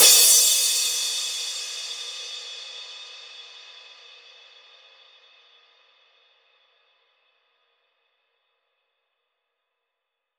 edm-crash-02.wav